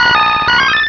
Cri d'Empiflor dans Pokémon Rubis et Saphir.